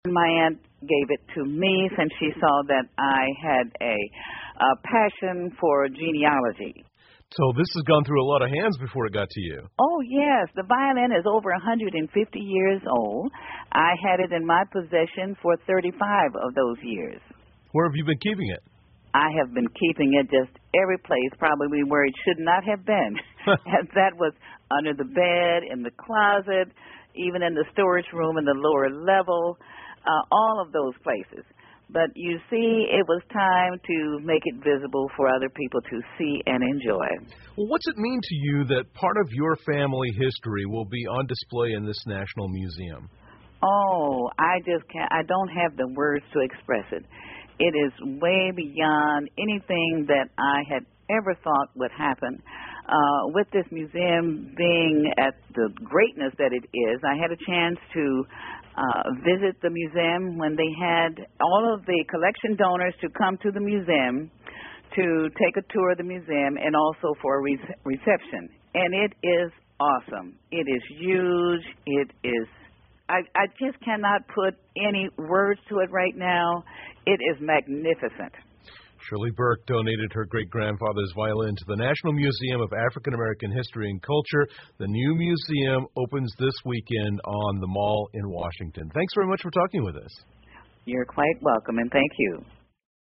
密歇根新闻广播 底特律人的传家宝在博物馆亮相 听力文件下载—在线英语听力室